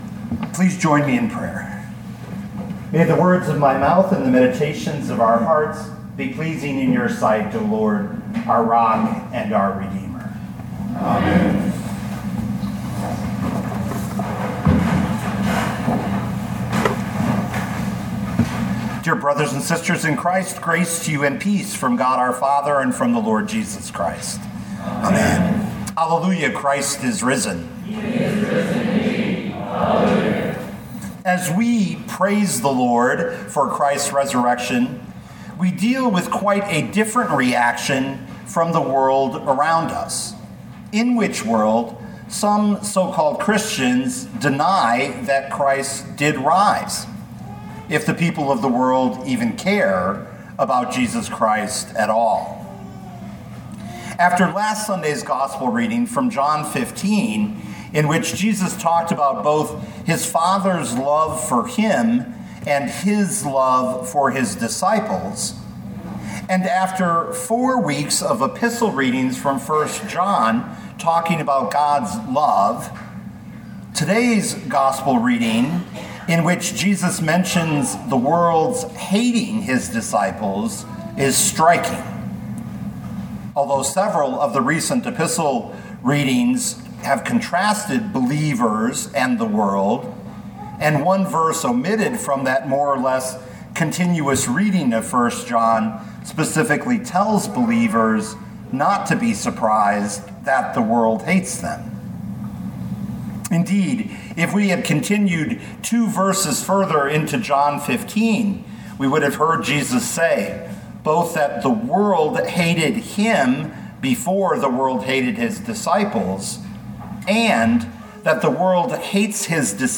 The Seventh Sunday of Easter, May 12, 2024